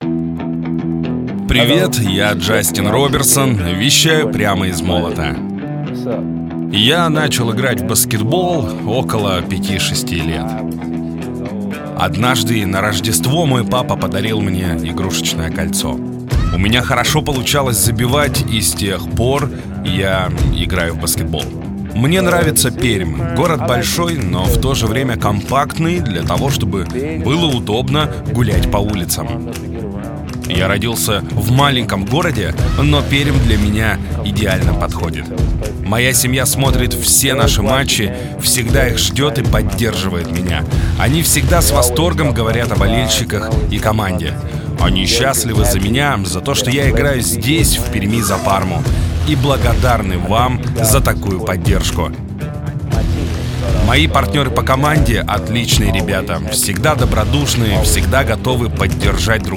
Тракт: Микрофон: Recording Tools MC-900 Пред: dbx-376 Карта: UA Apollo Twin
Демо-запись №1 Скачать